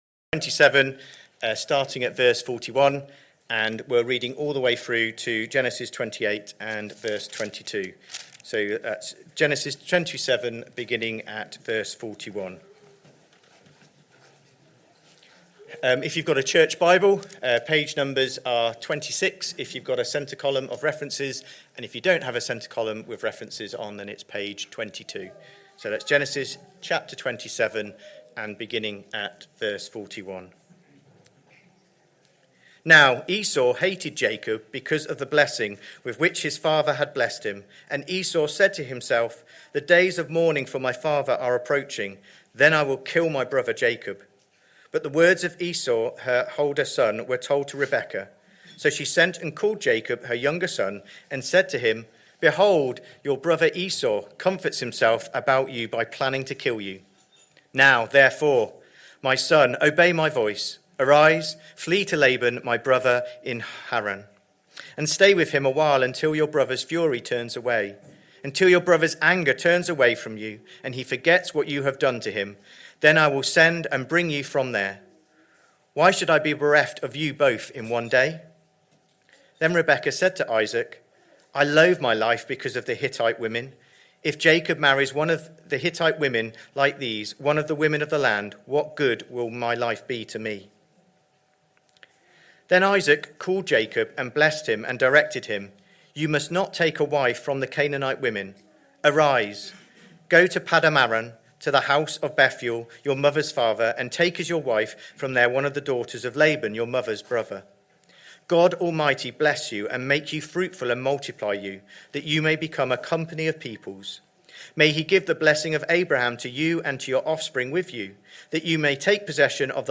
Christ Church Sermon Archive
Sunday Morning Service Sunday 20th July 2025 Speaker